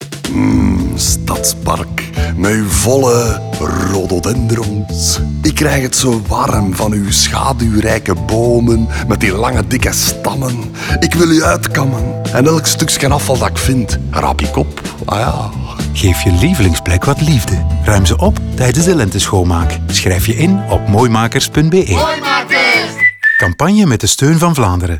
Die roept op om wat liefde te geven aan je lievelingsplek, door ze zwerfvuilvrij te maken tijdens de Lenteschoonmaak. De campagne bevat drie video’s, twee radiospots en een key visual die in allerlei media zal verschijnen.